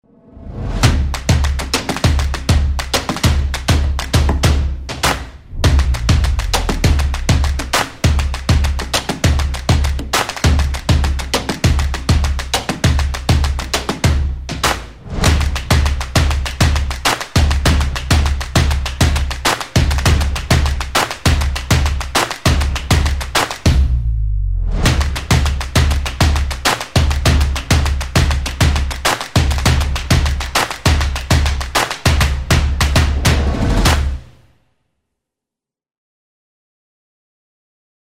TRILHA_STOMPS CLAPS.mp3